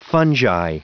Prononciation du mot fungi en anglais (fichier audio)
Prononciation du mot : fungi